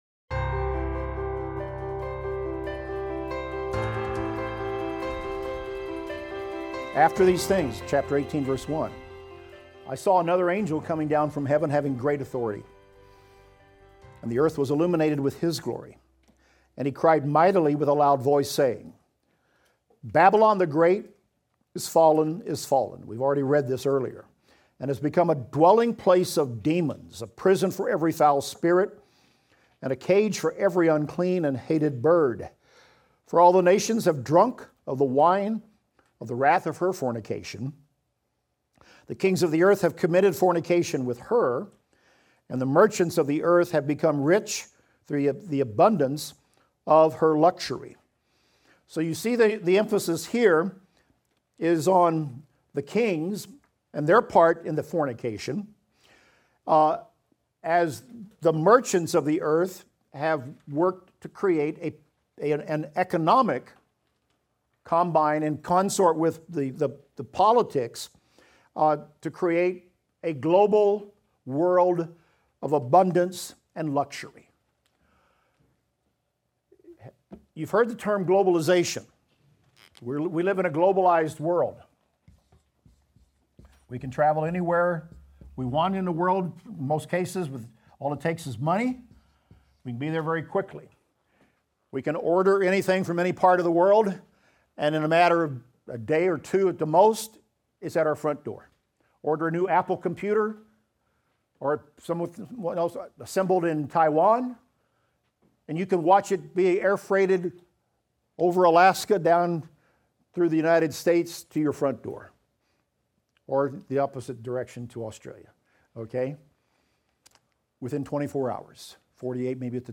Revelation - Lecture 49 - audio.mp3